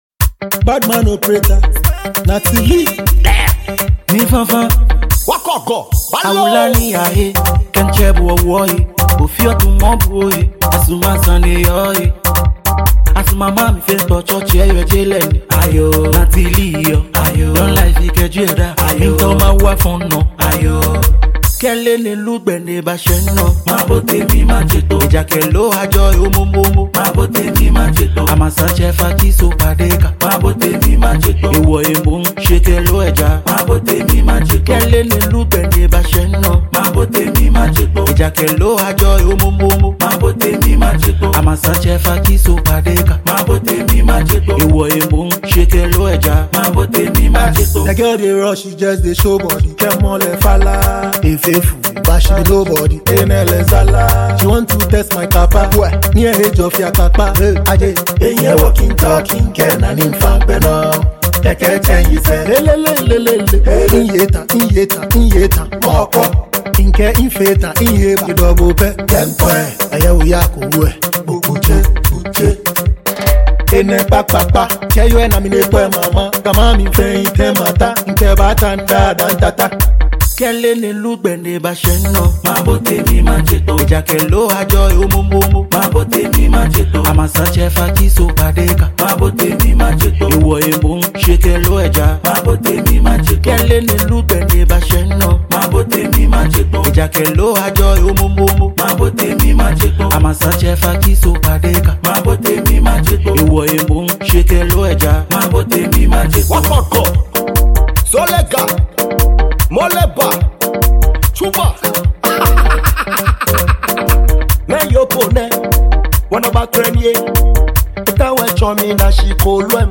Ghanaian dancehall
has the “asorkpor” vibe in it for your dance moves